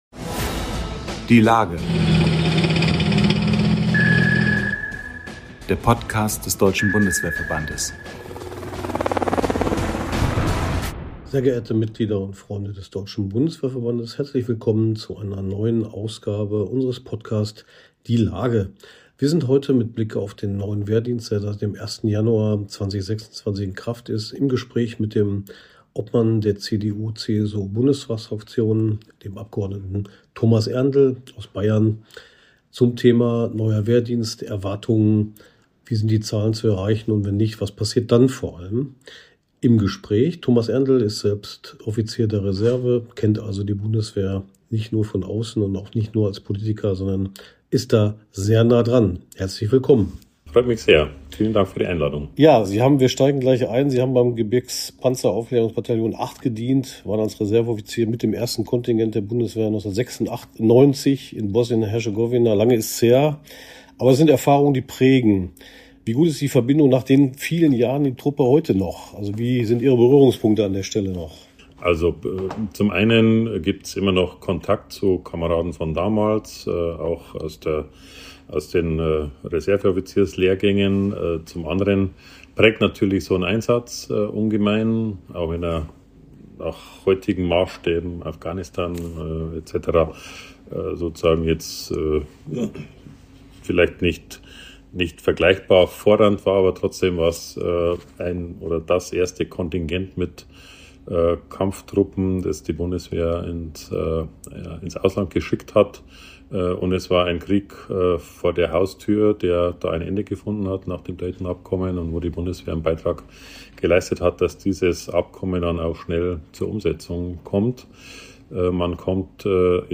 Im Gespräch beschreibt Erndl, wie viel er vom Neuen Wehrdienst erwartet und was passieren muss, wenn die Zahlen nicht ausreichen sollten.